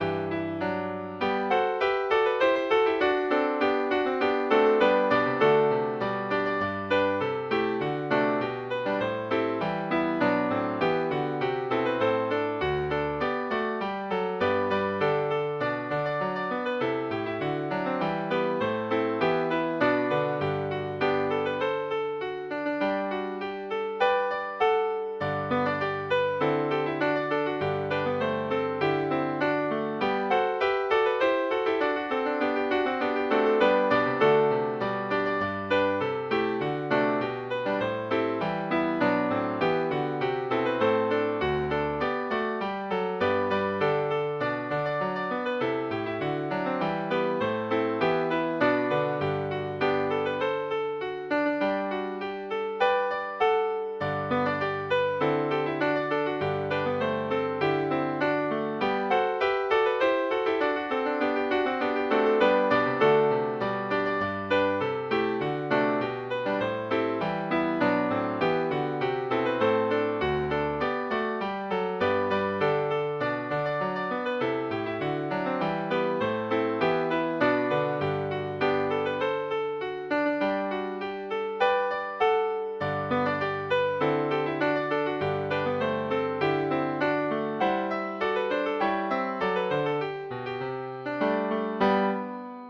Midi File, Lyrics and Information to William Taylor